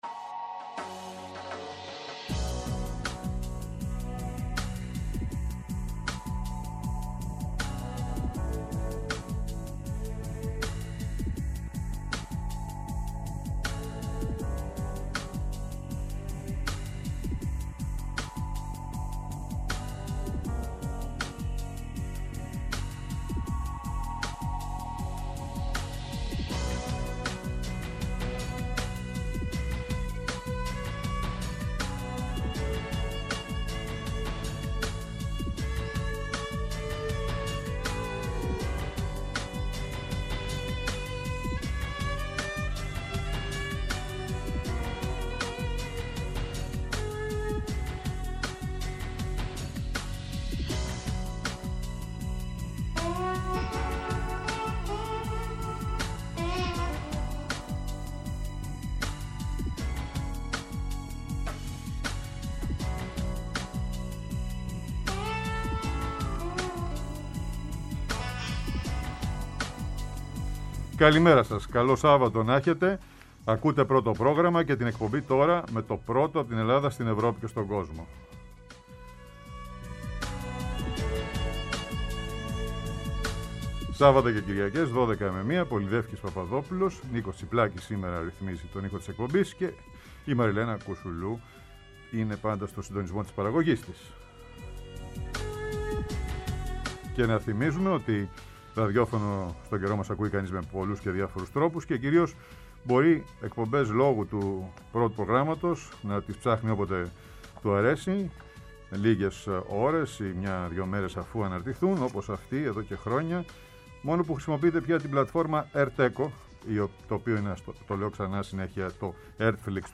Η ανατροπή του πολιτικού σκηνικού στη Σουηδία, μετά τις τελευταίες εκλογές, με καλεσμένο τον συγγραφέα Θοδωρή Καλλιφατίδη, που ζει και δραστηριοποιείται από το 1963 στην σκανδιναβική αυτή χώρα.